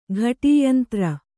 ♪ ghaṭīyantra